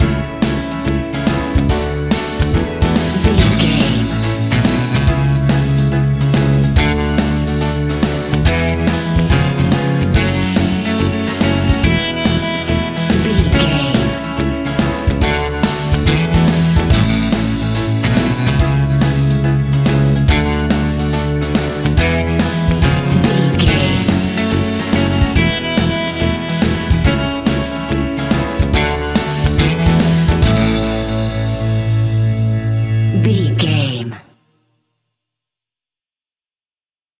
Uplifting
Ionian/Major
pop rock
fun
energetic
acoustic guitars
drums
bass guitar
electric guitar
piano
organ